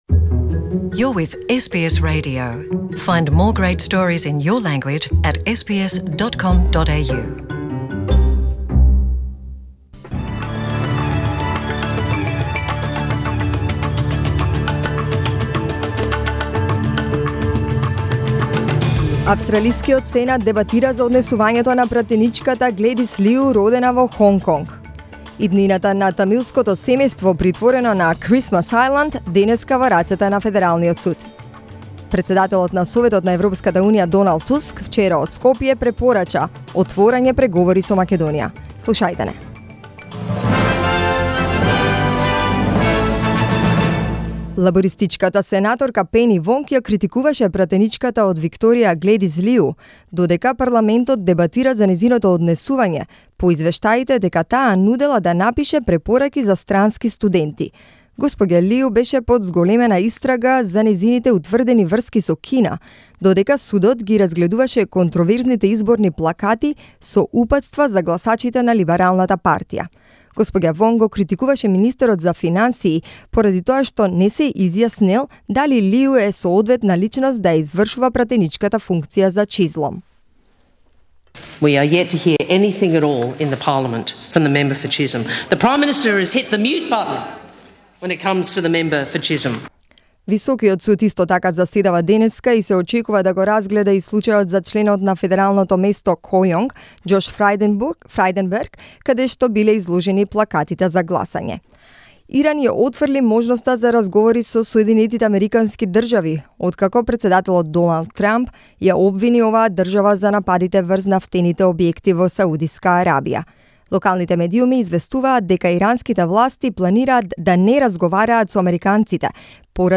Listen to SBS Radio news in Macedonian, 18th September